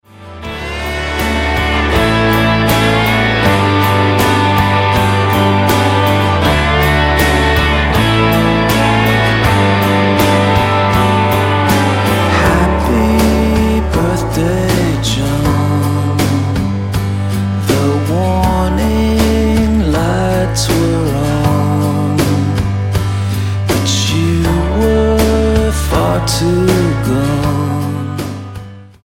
STYLE: Rock
slide guitar